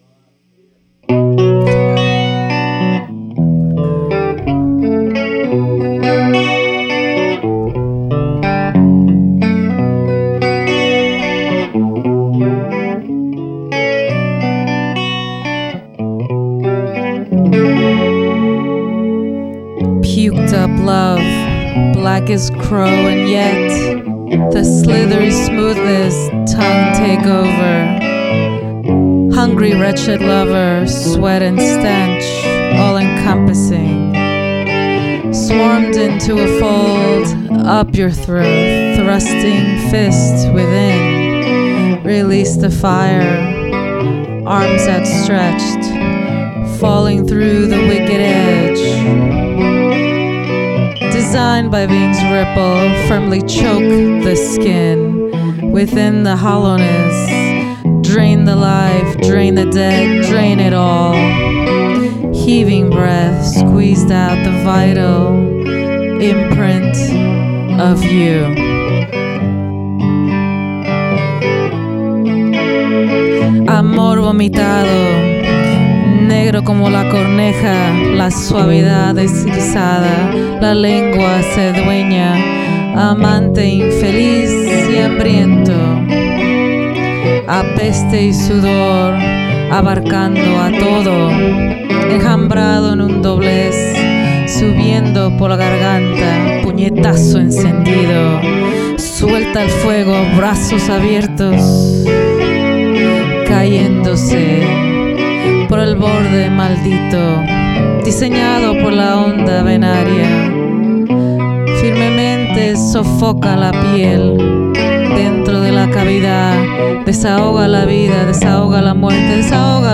Bilingual Poetry Backed by Music
spoken word
guitar